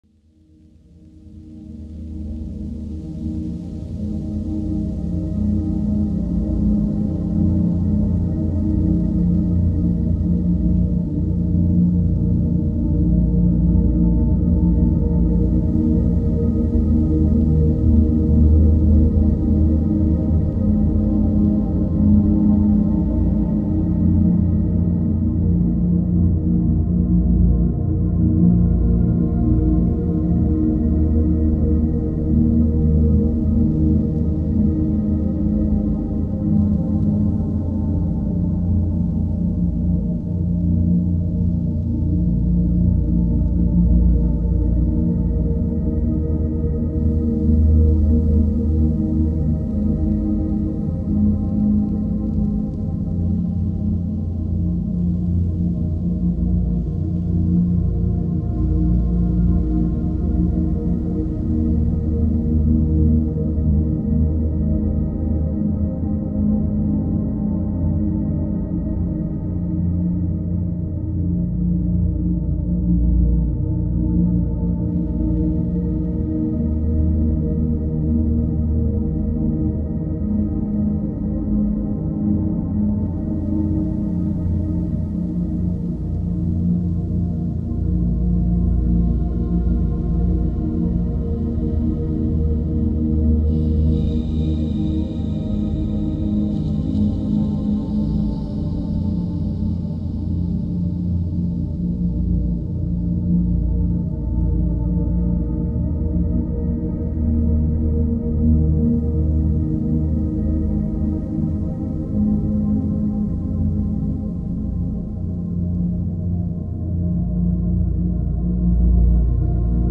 Ambient Deep Sleep Music
2_Nightime Drifting Turn Your Brain Off Music.mp3